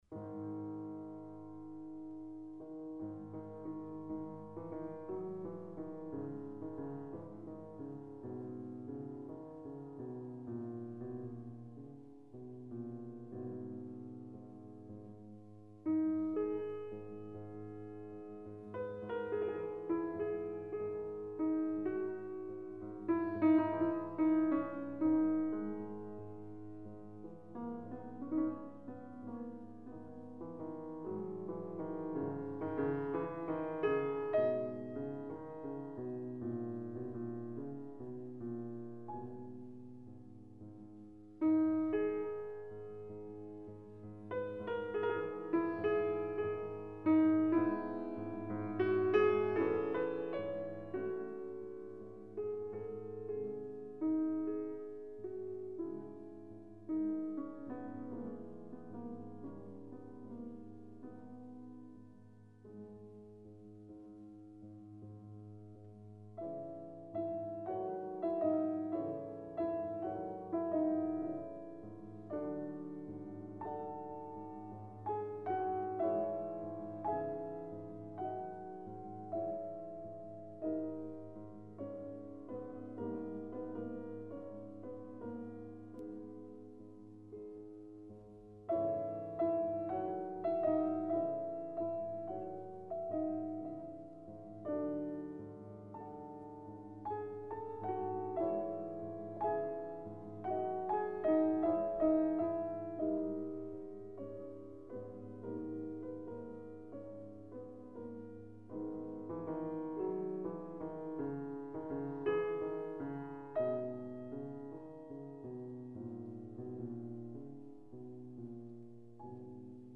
"Attorno alle antiche rovine risuona una nenia malinconica.
Il vecchio castello (versione pianistica)